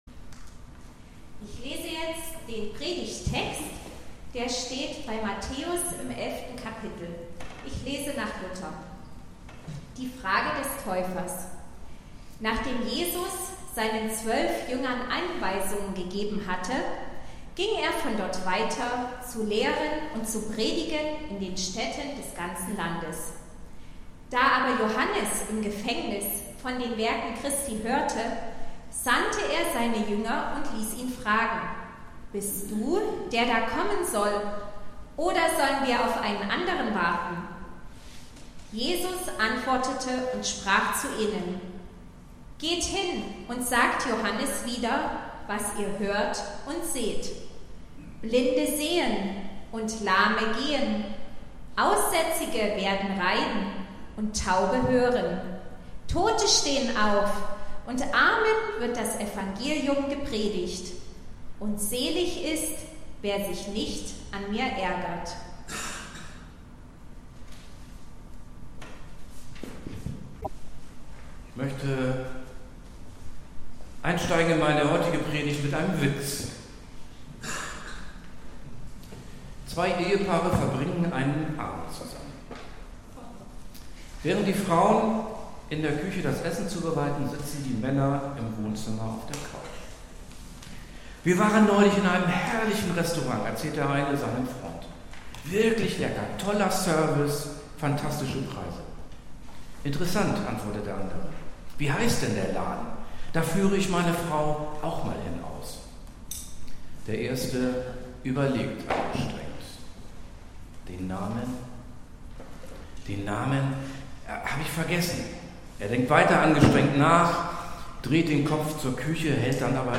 Gottesdienst